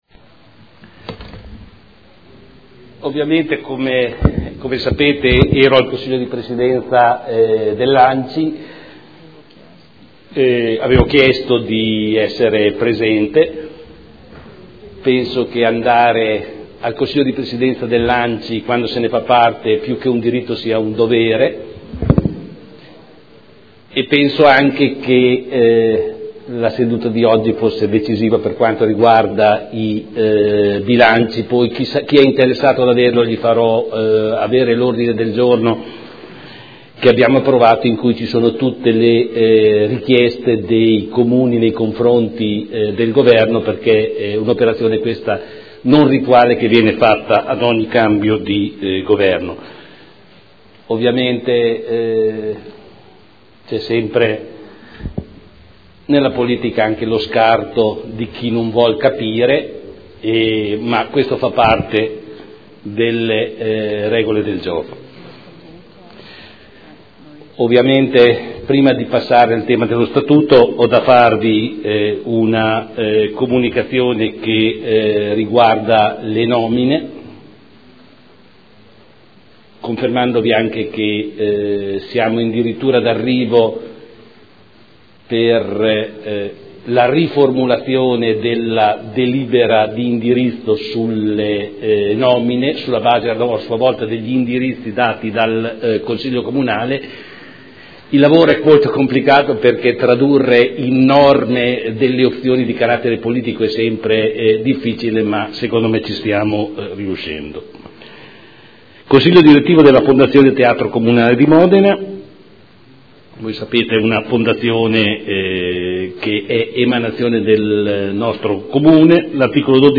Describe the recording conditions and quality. Seduta del 6 marzo. Comunicazione del Sindaco sulle nomine